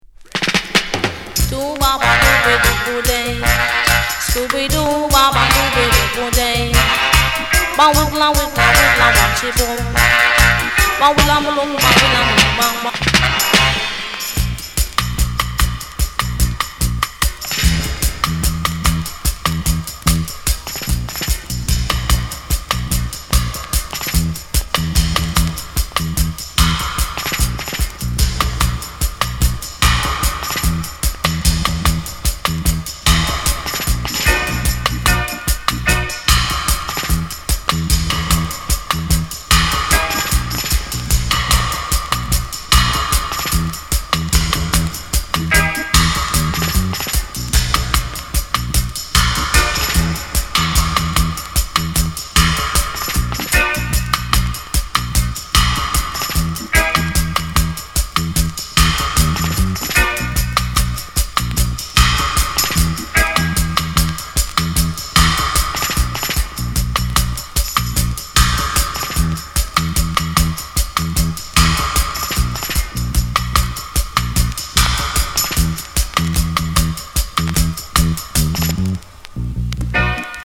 Genre: Reggae Format